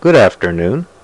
Good Afternoon Sound Effect
Download a high-quality good afternoon sound effect.